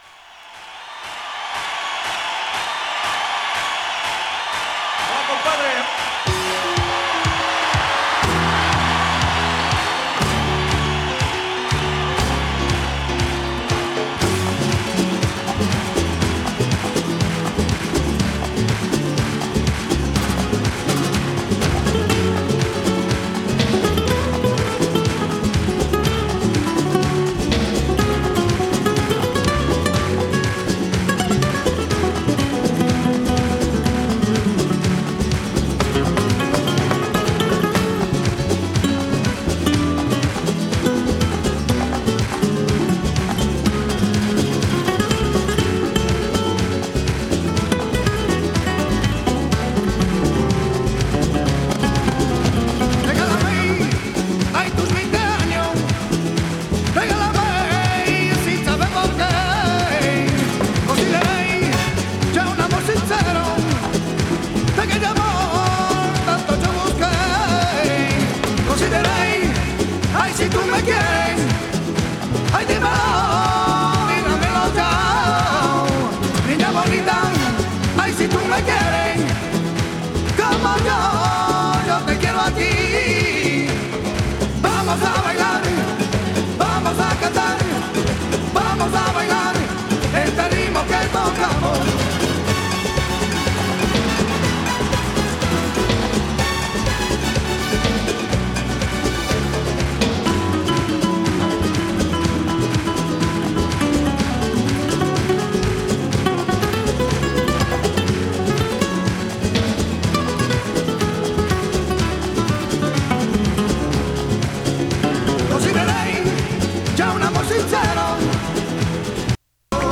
南仏出身のスペイン系メンバーによるグループ。
4.55)と、観客も参加のハンド・クラッピングが熱いライヴ・ヴァージョンを収録。
[3track 12inch]＊音の薄い部分に所々チリパチ・ノイズ。